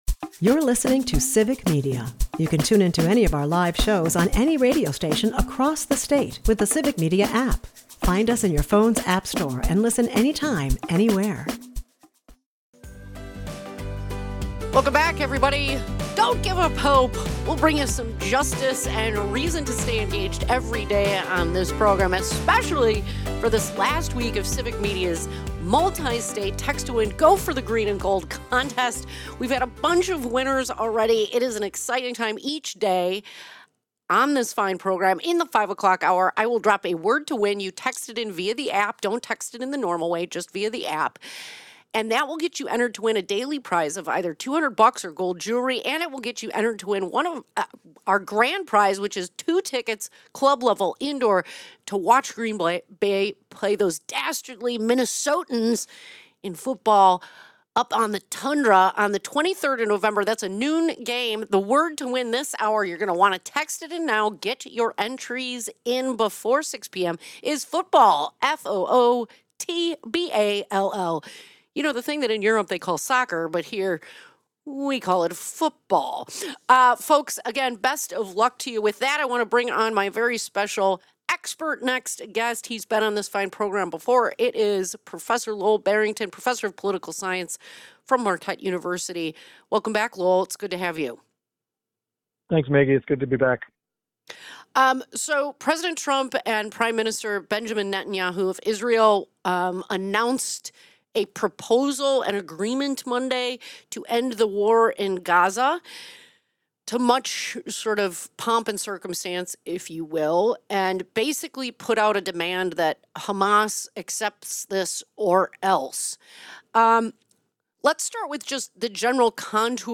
Later, listeners weigh in on topics ranging from massive data centers to Fox News’ incendiary rhetoric, all against the backdrop of a looming government shutdown tied to healthcare subsidies.